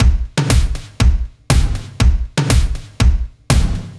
Index of /musicradar/french-house-chillout-samples/120bpm/Beats
FHC_BeatB_120-01_KikSnrTom.wav